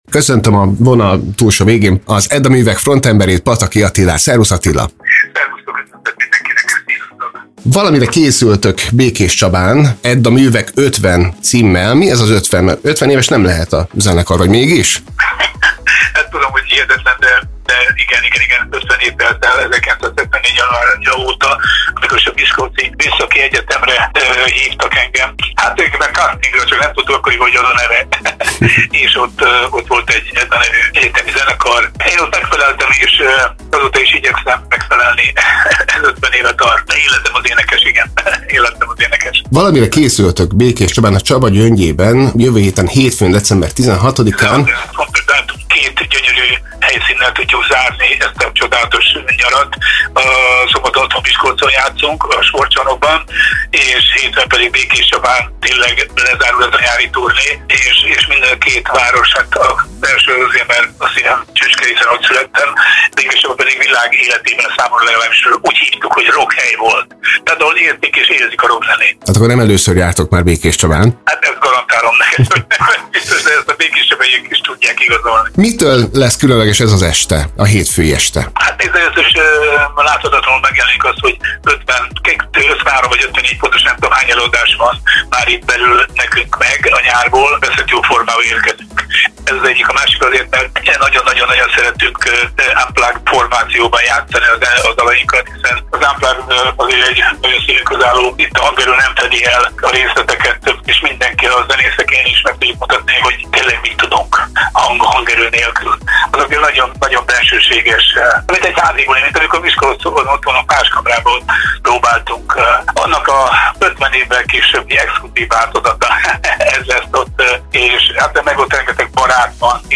Fennállásának 50. évfordulóját az együttes egy unplugged turnéval ünnepli 2024-ben, melynek utolsó állomása lesz a békéscsabai. A részletekről Pataky Attila, a zenekar frontembere mesélt nekünk.